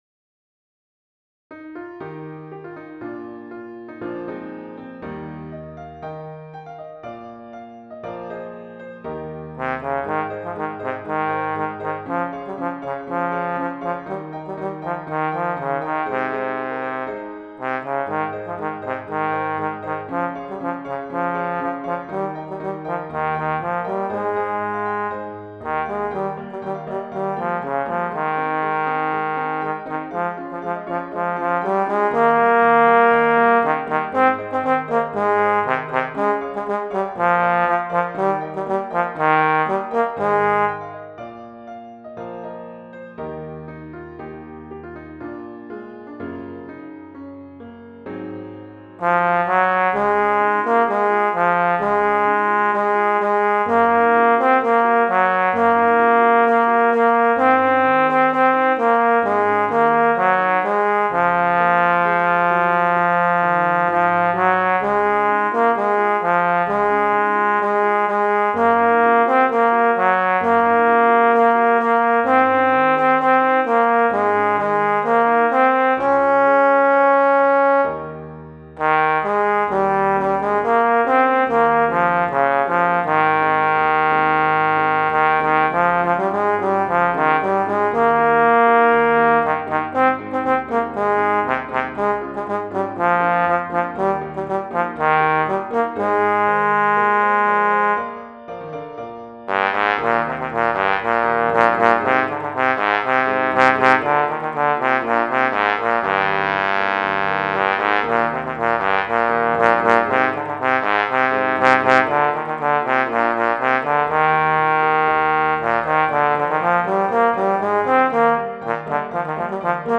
Intermediate Instrumental Solo with Piano Accompaniment.
Christian, Gospel, Sacred.
set to a fast past, energetic jig.